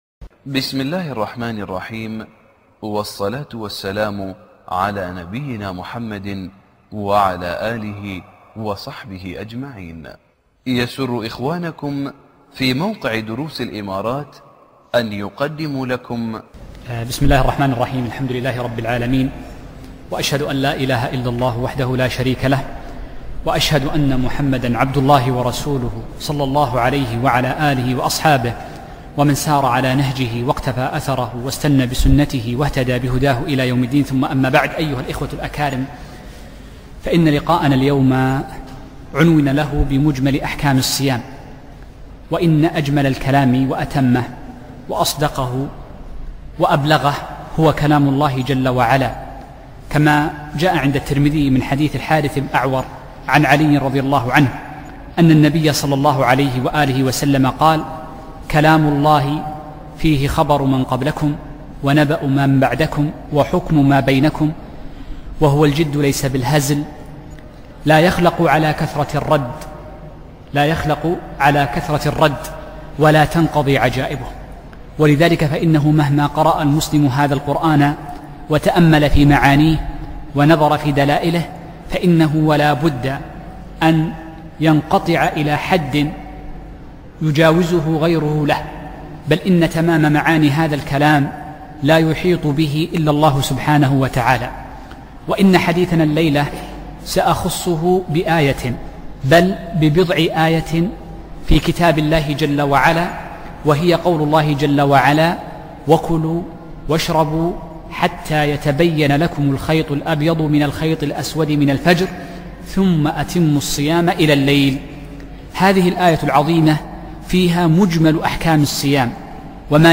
محاضرة - مجمل أحكام الصيام والتراويح